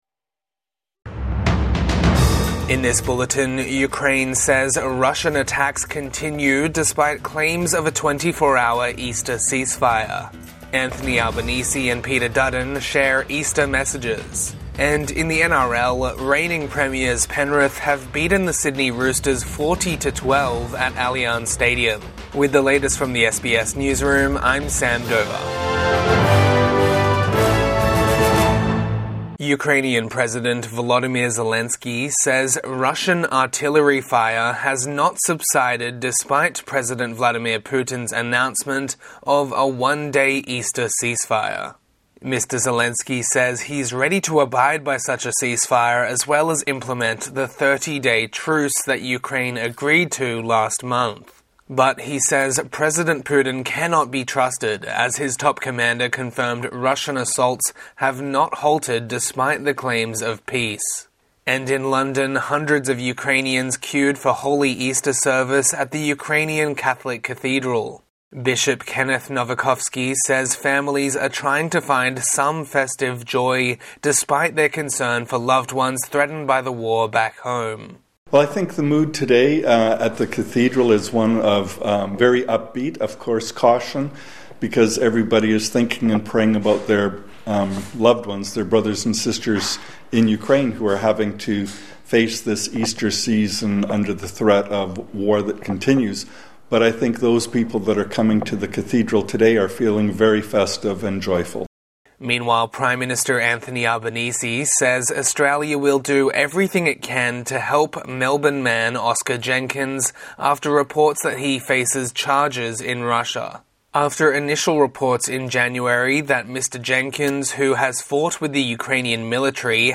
Russian attacks continue despite Easter truce | Morning News Bulletin 20 April 2025